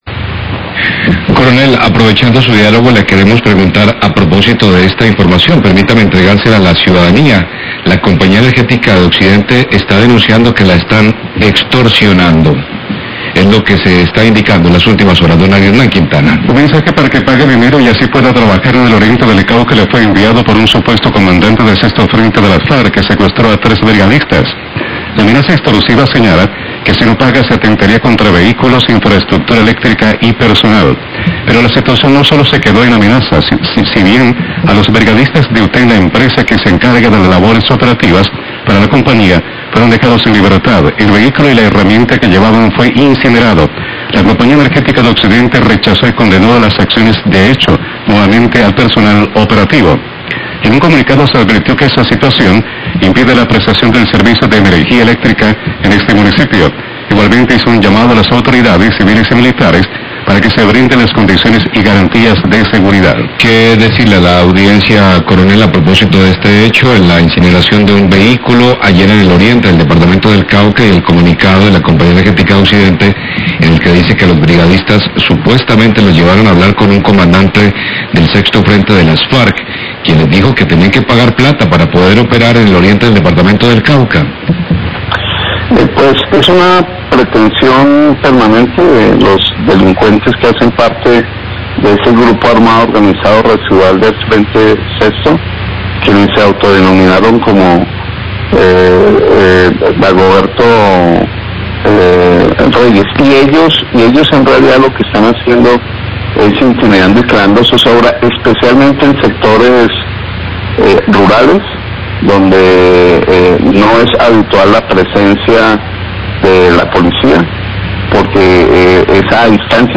Radio
La Compañía Energética denuncia que está siendo víctima de extorsión a raíz de un comunicado que les fue enviado por disidencias de las Farc luego de del secuestro de 3 operarios y la incineración de una camioneta y herramientas. Declaraciones del Comandante de la Policía Cauca, Coronel Fabio Rojas.